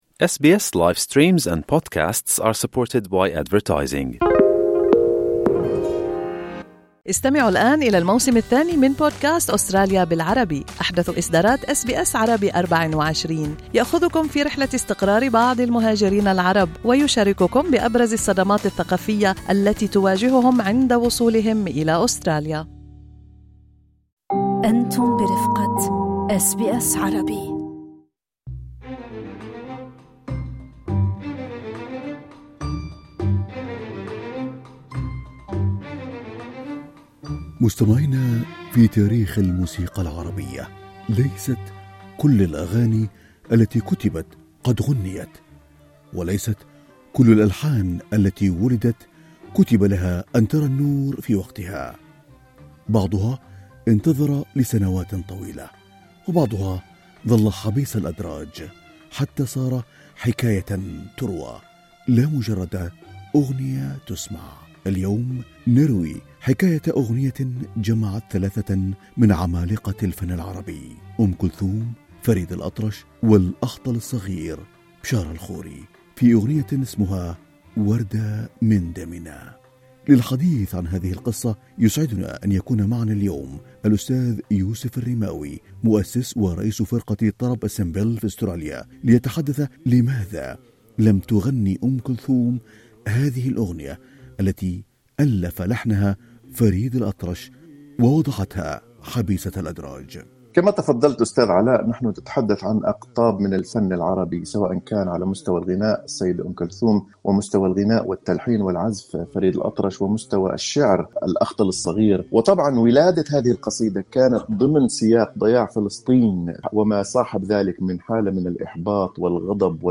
لقراءة محتوى التقرير الصّوتي، اضغط على خاصيّة Transcription في الصورة أعلاه. للاستماع لتفاصيل اللقاء، اضغطوا على زر الصوت في الأعلى.